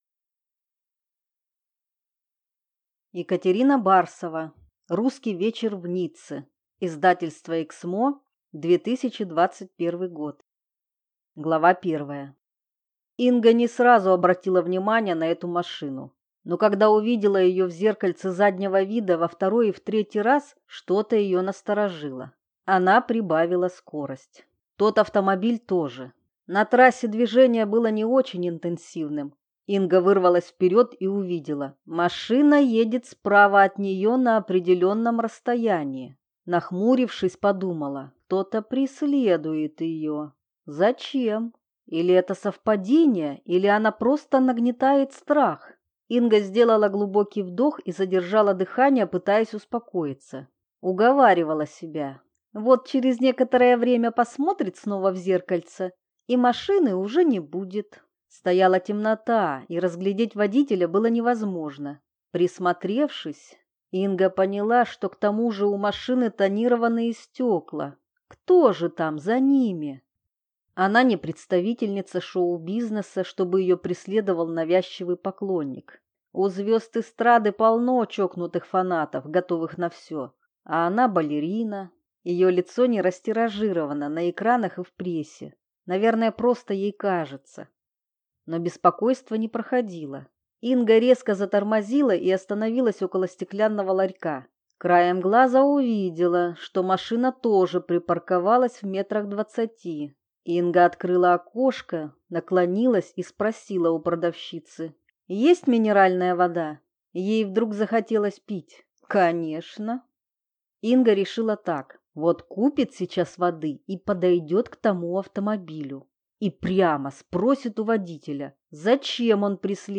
Аудиокнига Русский вечер в Ницце | Библиотека аудиокниг